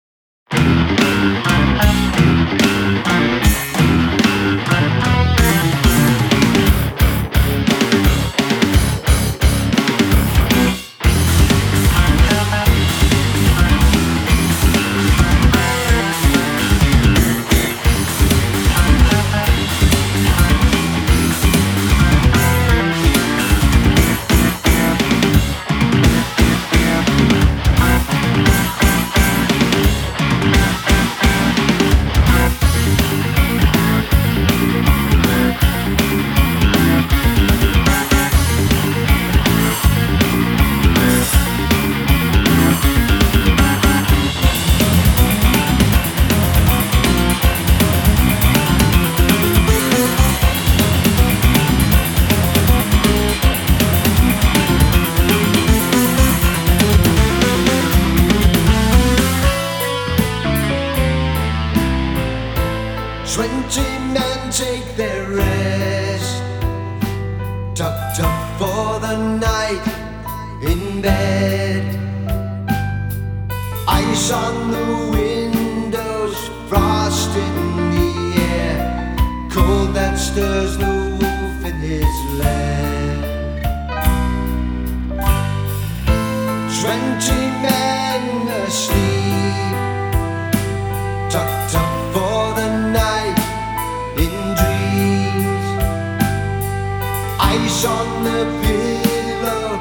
keyboards/drums
bass/lead vocals
guitars/backing vocals
It's a dark album that draws you in, and holds you there…